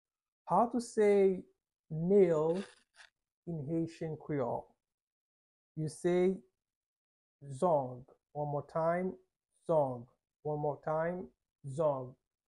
How to say "Nail" in Haitian Creole - "Zong" pronunciation by a native Haitian tutor
“Zong” Pronunciation in Haitian Creole by a native Haitian can be heard in the audio here or in the video below:
How-to-say-Nail-in-Haitian-Creole-Zong-pronunciation-by-a-native-Haitian-tutor.mp3